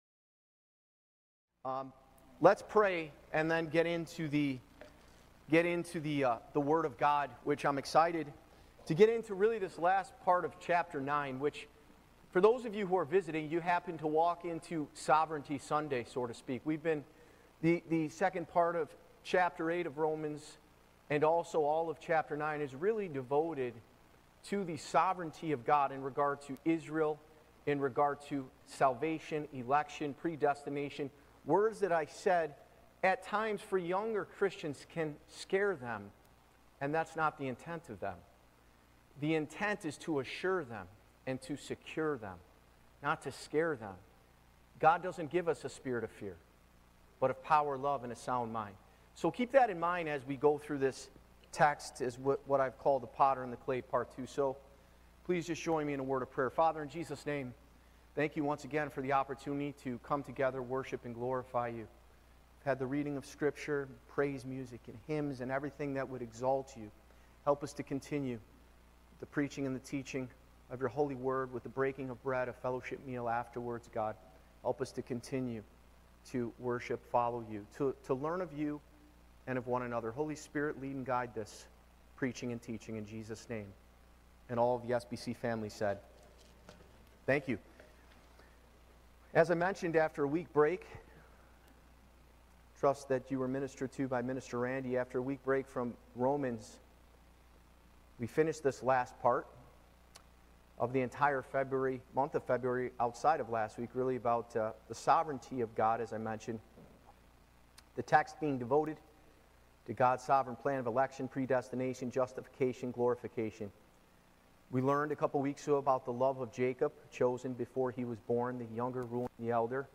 Church Location: Spencerport Bible Church
Live Recording